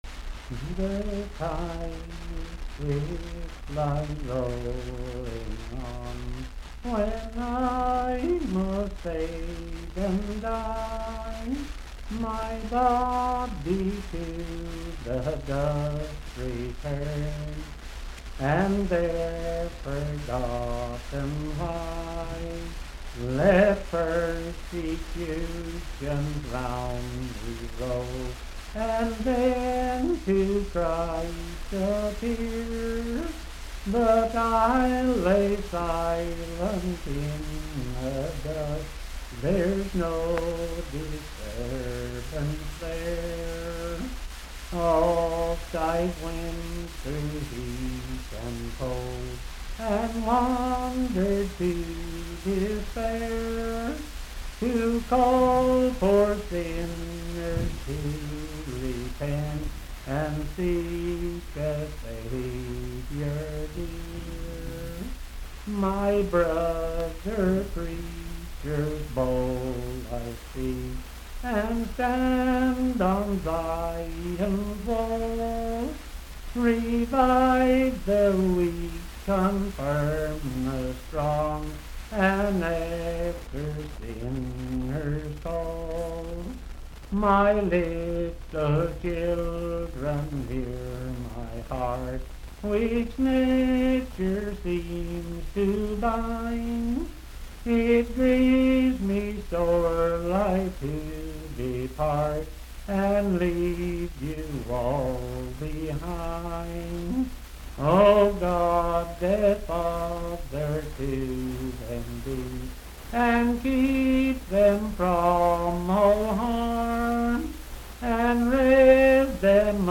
Unaccompanied vocal music
in Dryfork, WV.
Verse-refrain 9(4).
Voice (sung)